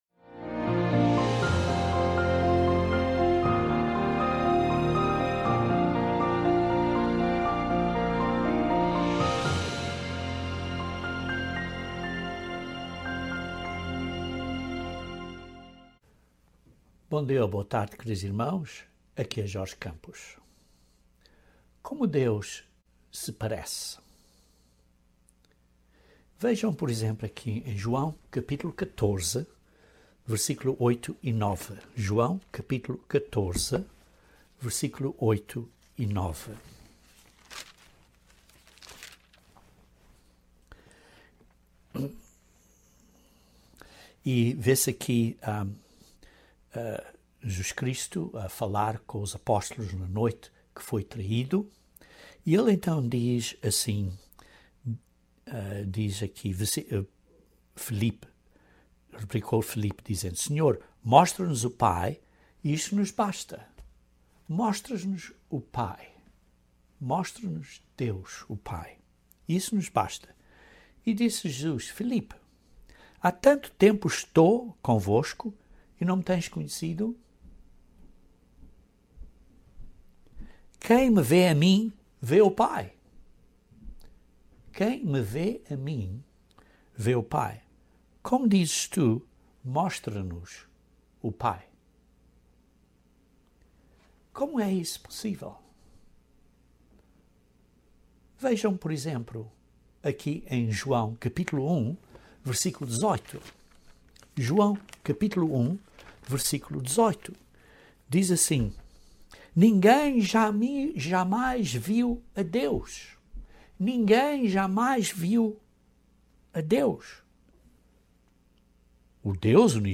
Como Deus apareceu no Antigo Testamento, visto que Jesus Cristo disse que nimguém viu ou ouviu o Pai? Este sermão responde a esta questão.